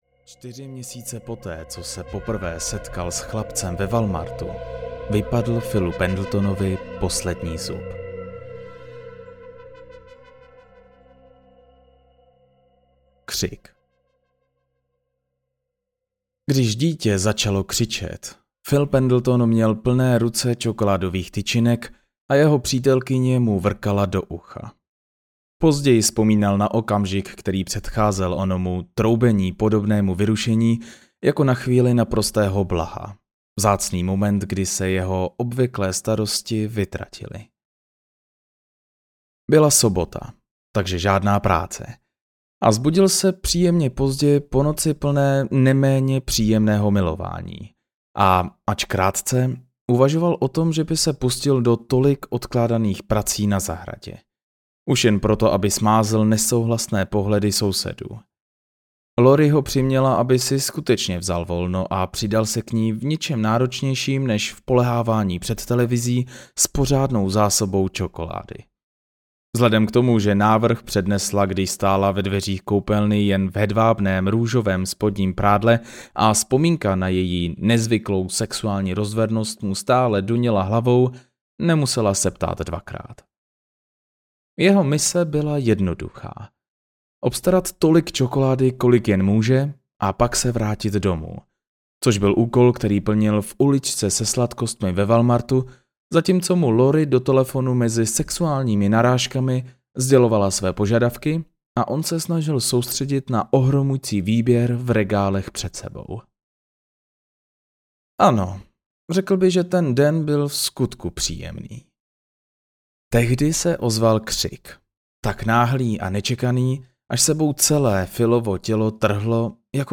Kyselé bonbony audiokniha
Ukázka z knihy